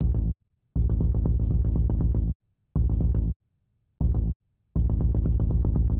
Then, that piece was copied to make a new sound.